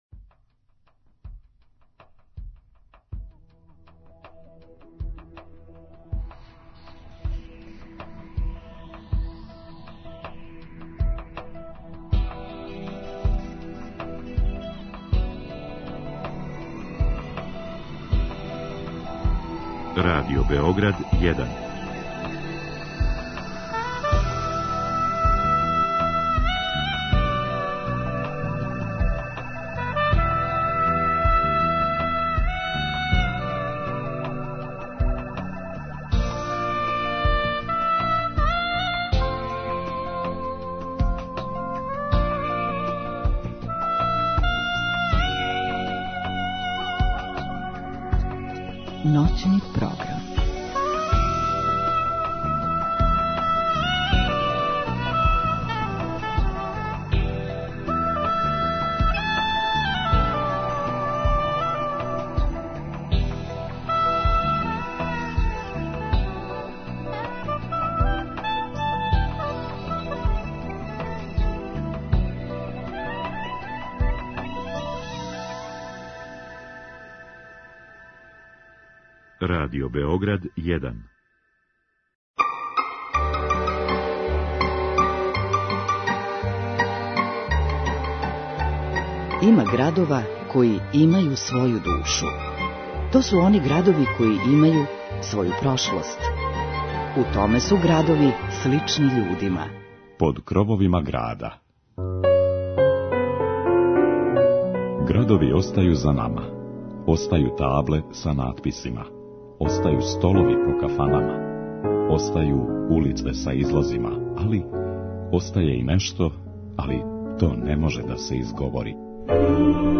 Ноћни програм биће обојен пригодном изворном музиком у складу са предстојећим празником.